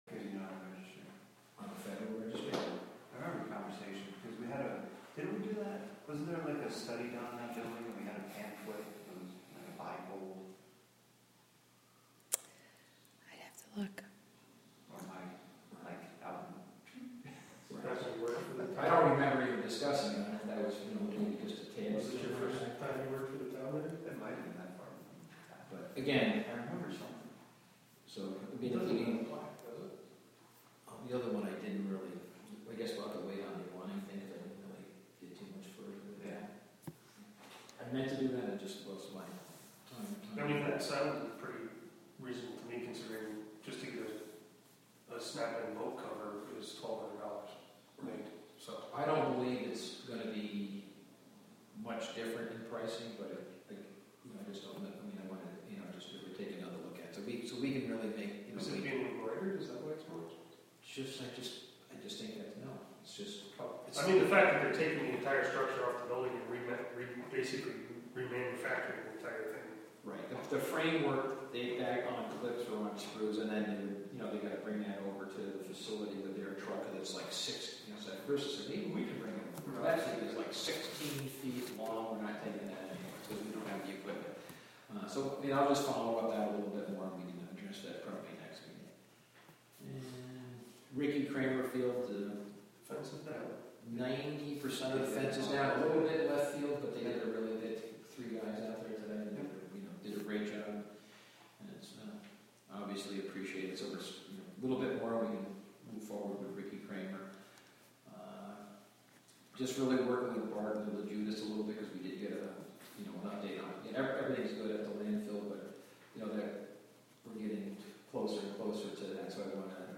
Live from the Town of Catskill: Town Board Meeting September 15, 2021 (Audio)